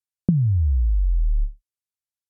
Quick Tip: Make a Bass Drop Sound Like Nelly Furtado’s “One Trick Pony”